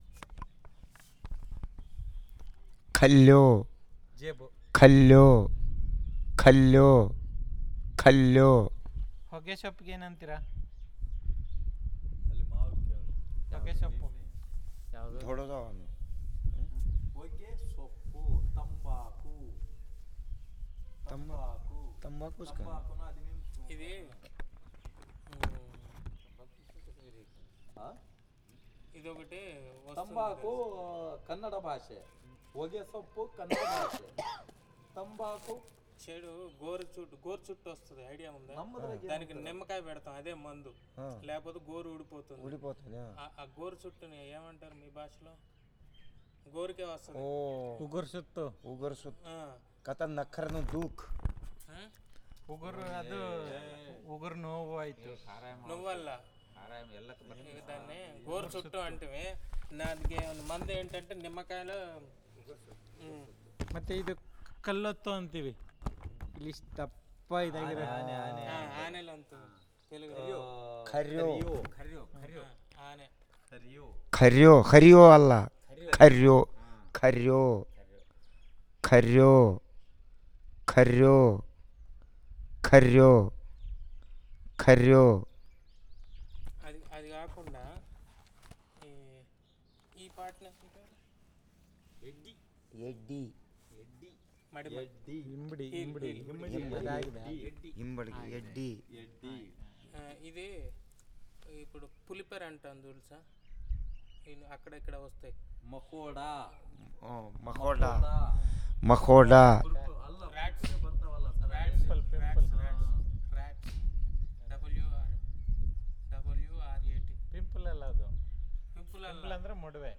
Elicitation of words about tobacco, tumor, and disease bleb - part I